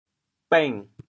国际音标 [pεŋ]
bêng6.mp3